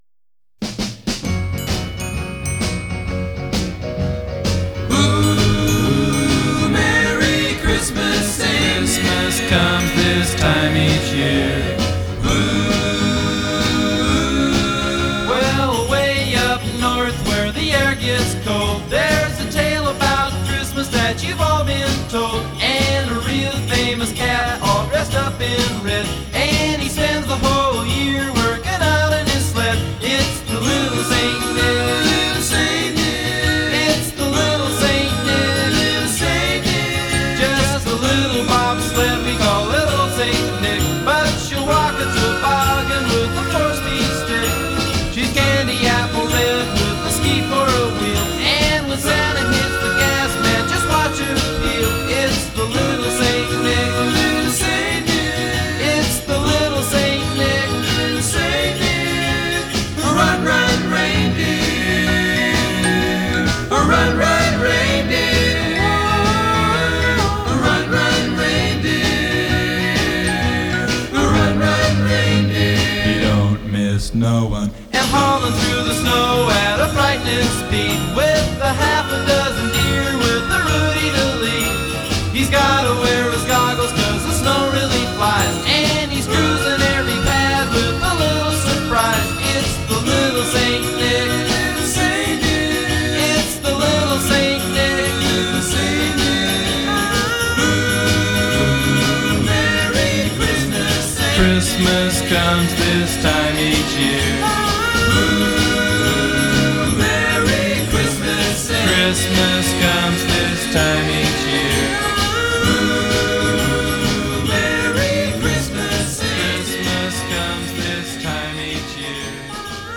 This is a stereo mix of that original single version.